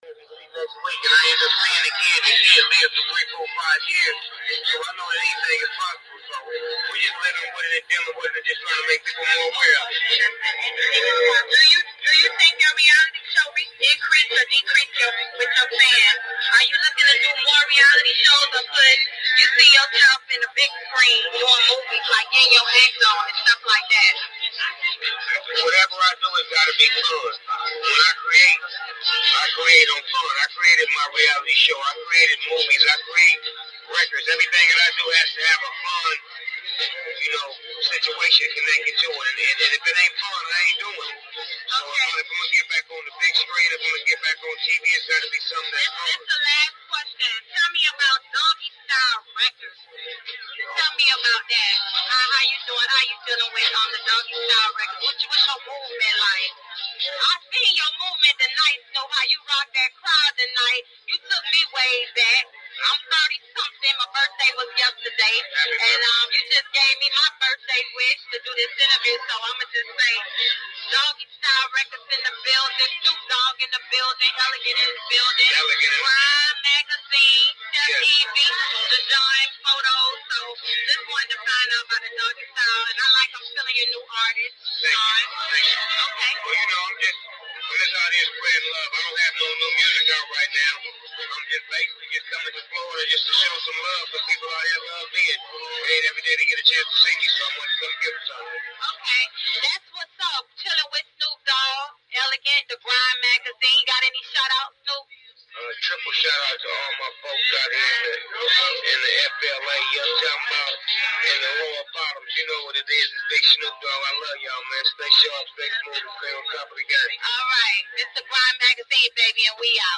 INTERVIEWS
Snoop Dog Interview2.mp3